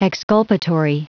Prononciation du mot exculpatory en anglais (fichier audio)